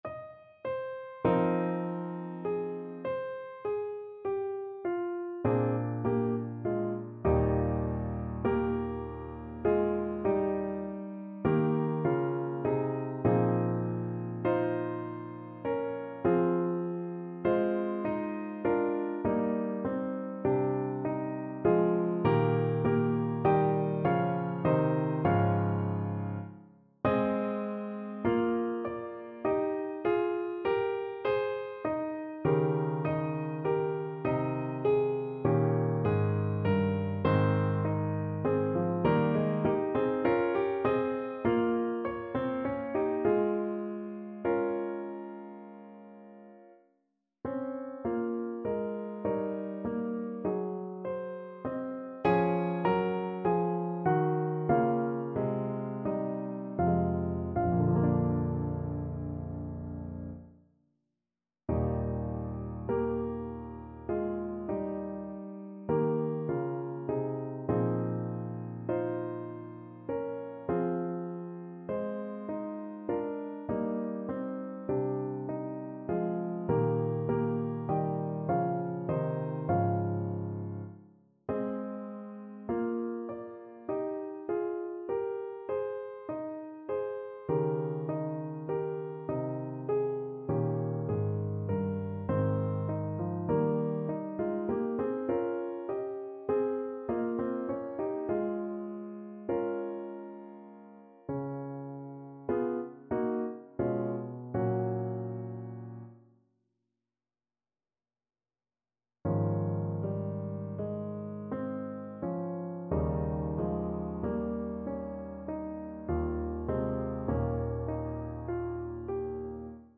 5/4 (View more 5/4 Music)
Classical (View more Classical Trumpet Music)